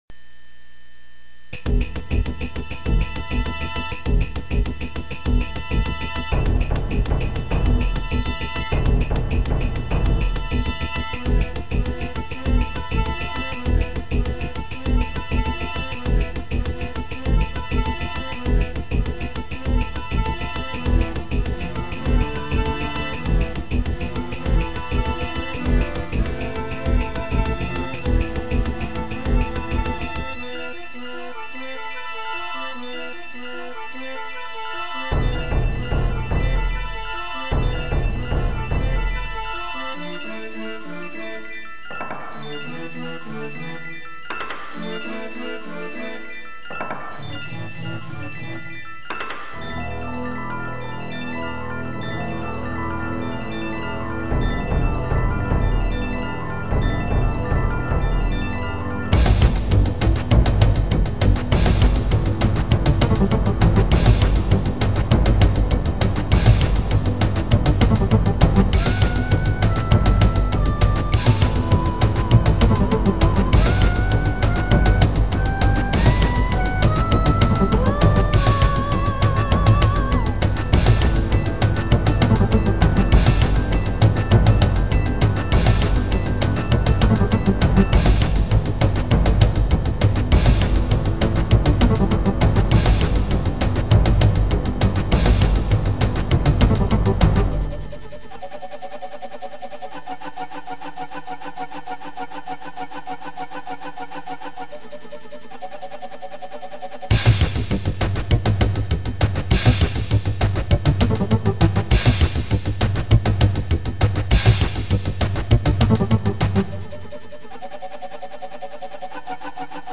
CM-300という昔の音源を使っています。
全ての曲はヘッドホンで聞くように設計されてます。
魂を鼓舞するというか、うるさいかも知れない。長いので途中まででカット。